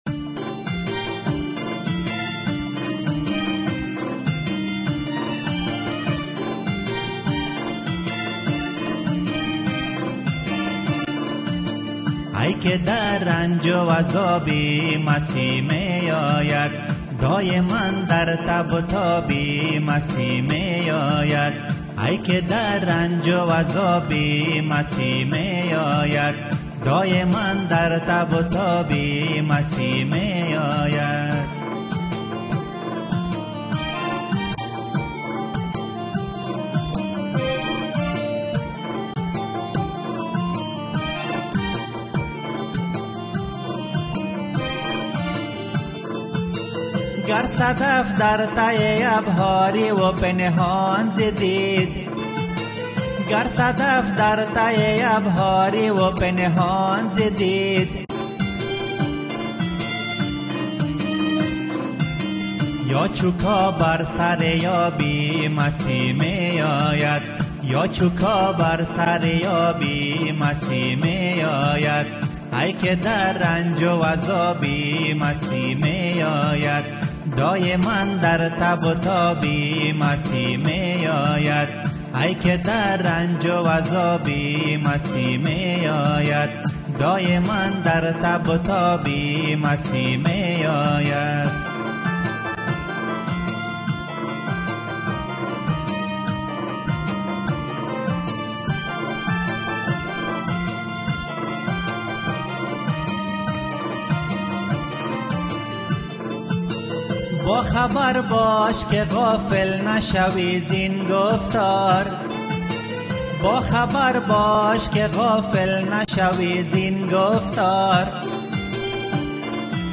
پرستش > سرودها